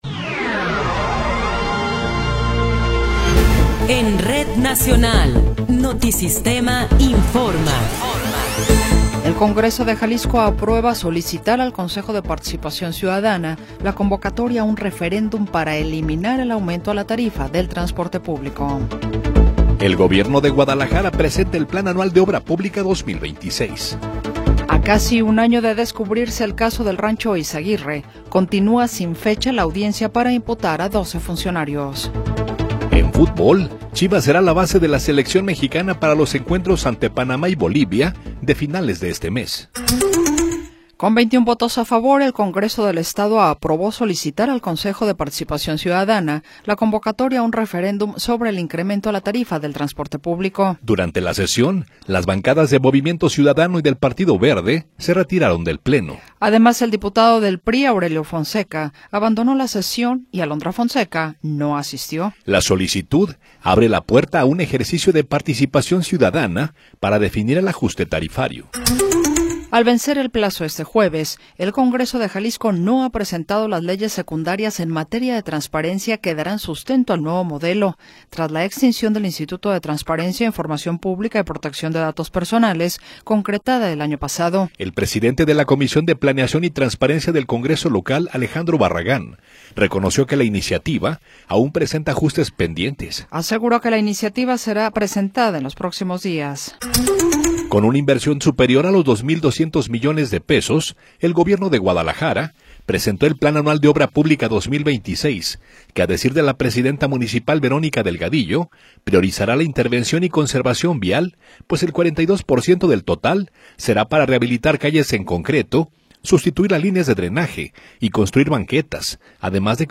Noticiero 20 hrs. – 15 de Enero de 2026
Resumen informativo Notisistema, la mejor y más completa información cada hora en la hora.